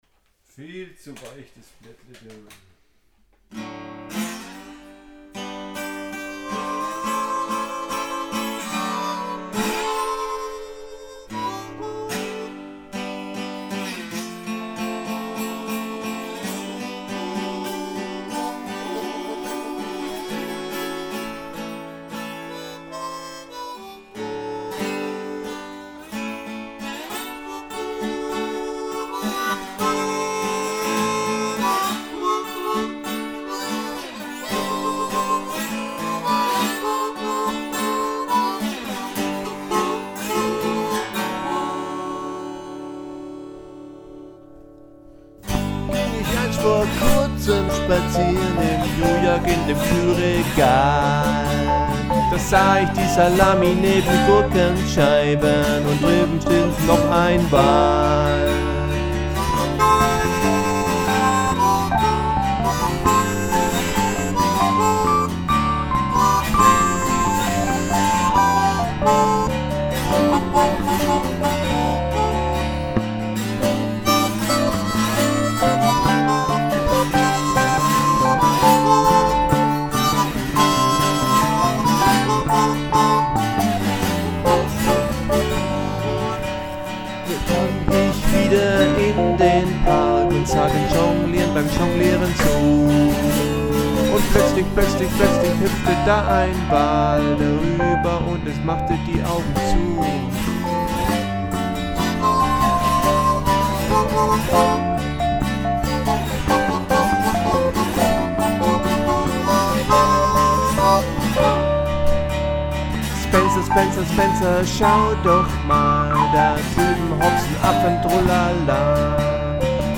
Comedy Blues Stück.
Tempo: 72 bpm / Datum: 29.10.2014